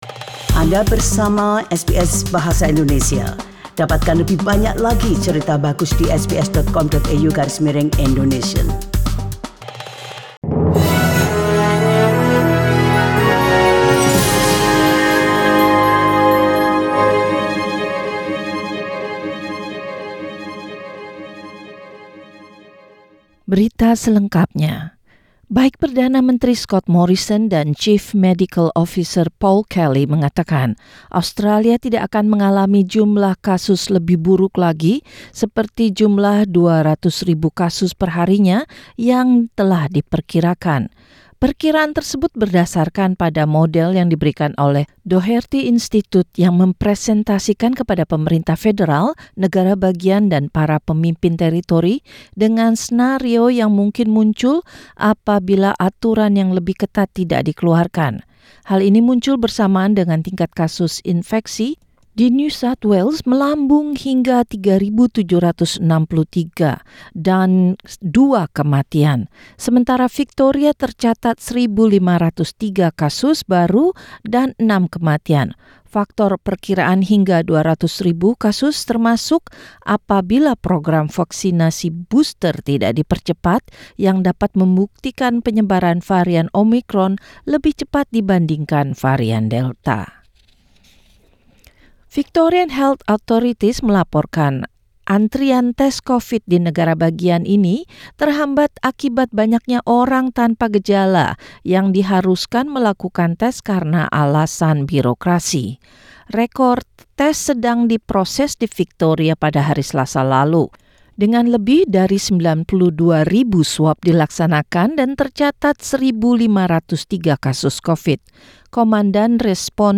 SBS Radio News in Indonesian - Wednesday, 22 December 2021
Warta Berita Radio SBS dalam Bahasa Indonesia Source: SBS